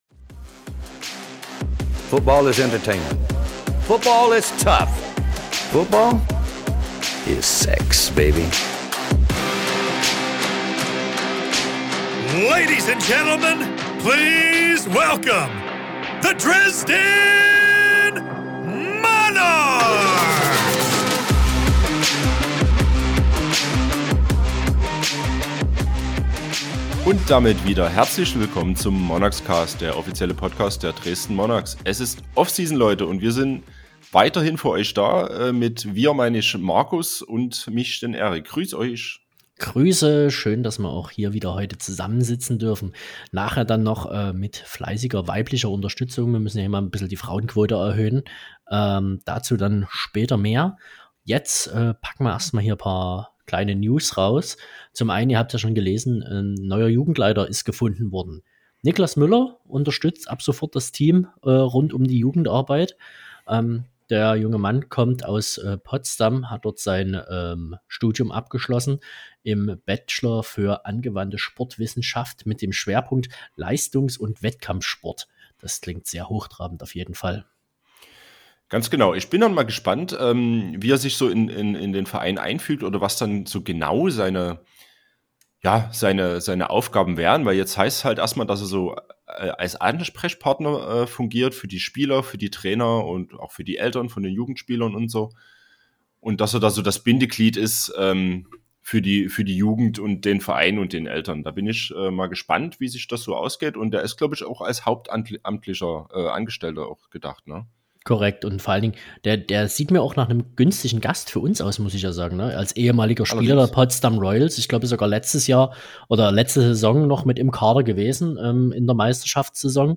Offseason-Zeit ist Interview-Zeit.